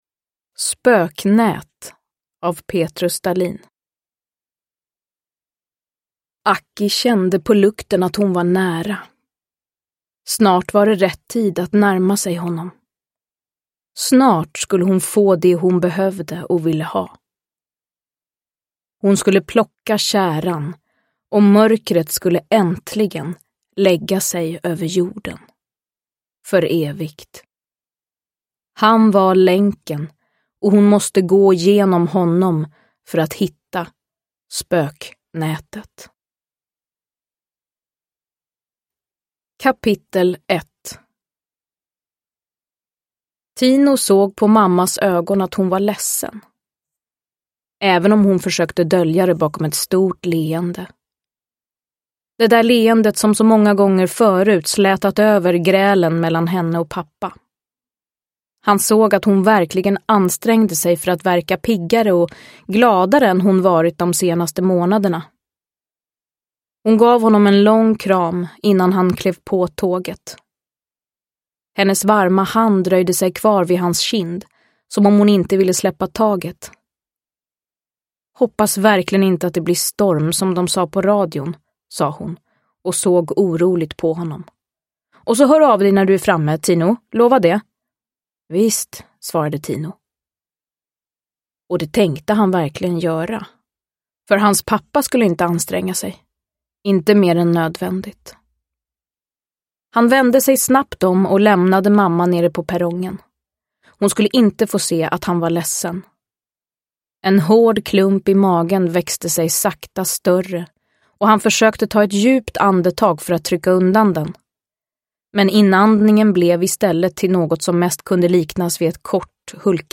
Spöknät – Ljudbok – Laddas ner
Uppläsare: Frida Hallgren